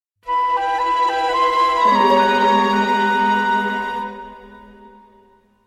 SFX影视悬疑片转场音效下载
SFX音效